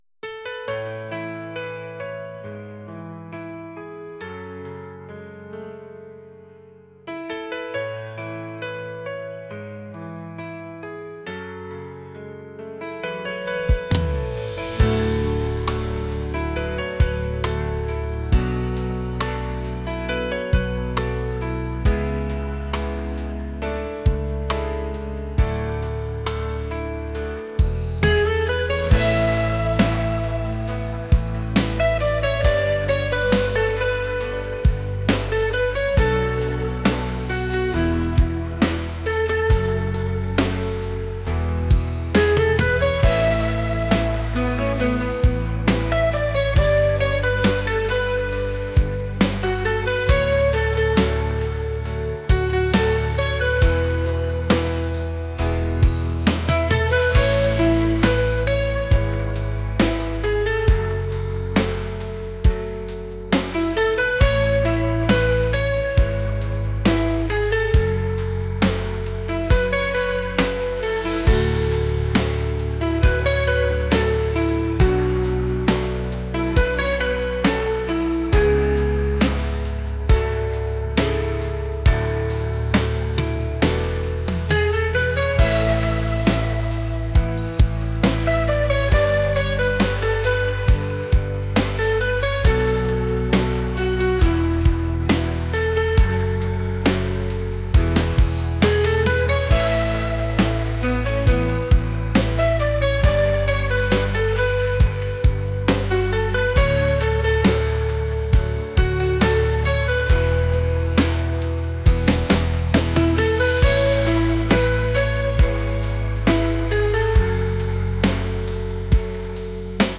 pop | acoustic | cinematic